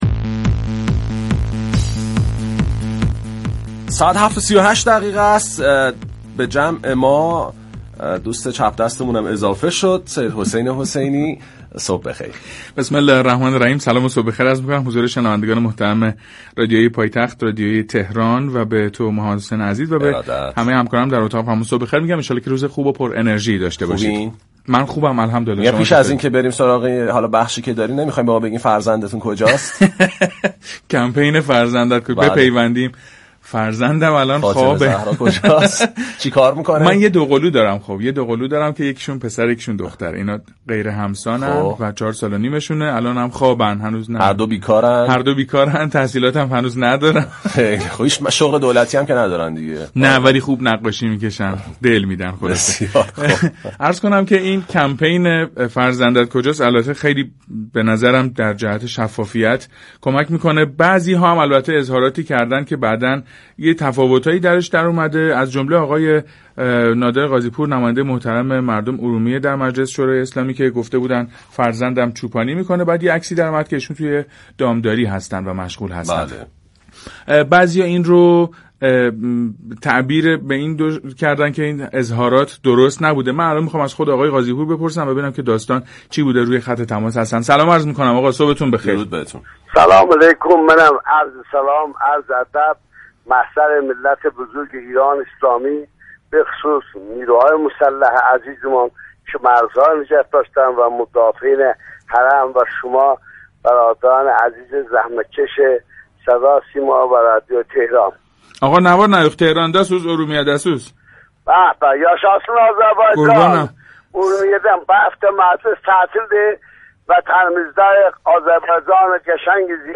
توضیحات نادر قاضی پور درباره چوپان بودن فرزندش در گفتگوی زنده با رادیو تهران